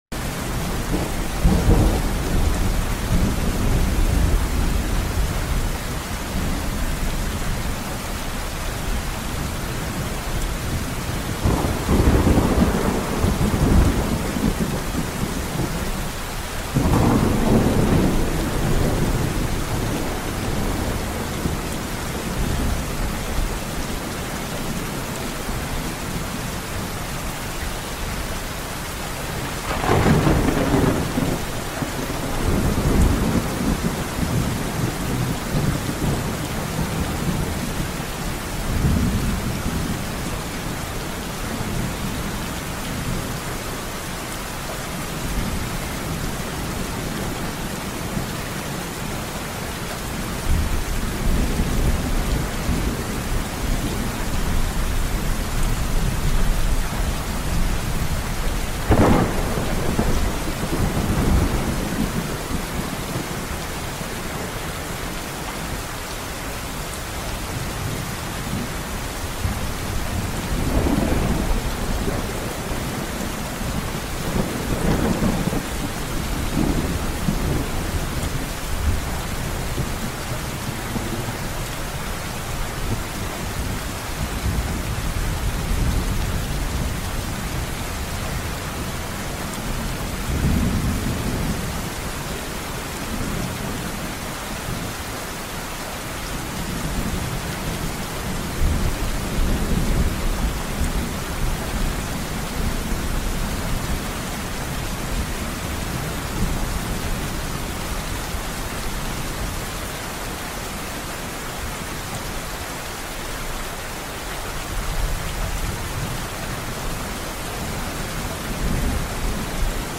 🌧⚡ This 30-minute rain & thunderstorm soundscape will calm your mind and help you unwind. Perfect for deep sleep, meditation, or focus.